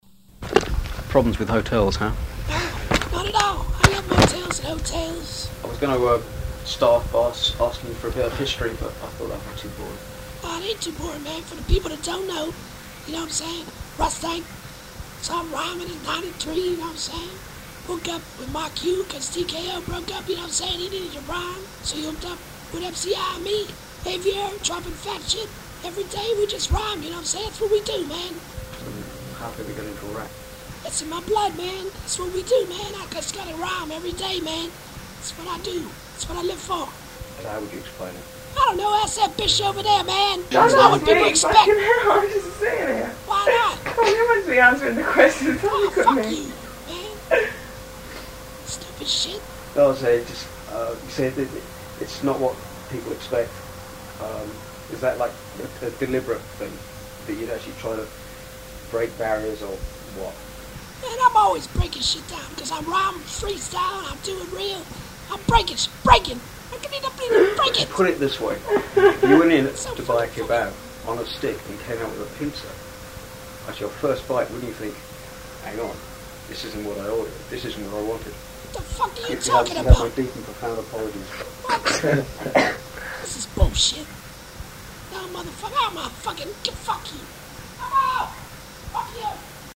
This is an interview
a British reporter